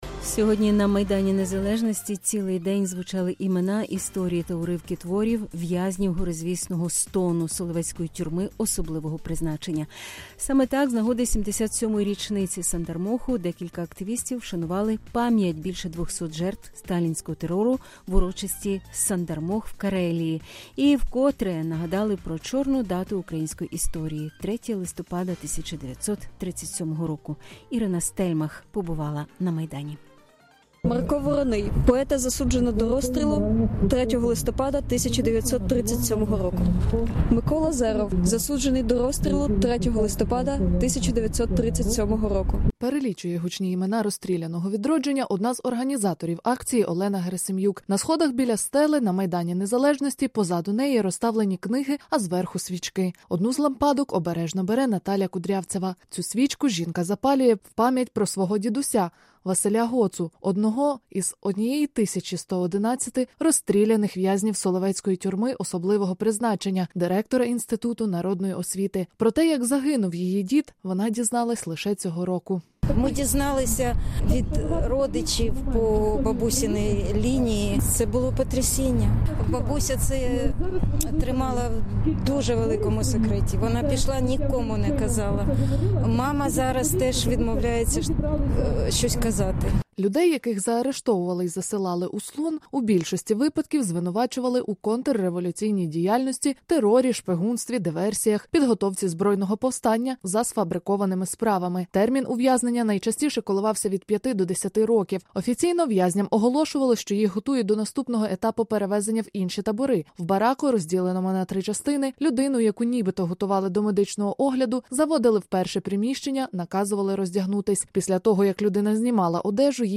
У центрі Києва цілий день звучали імена в’язнів Сандармоху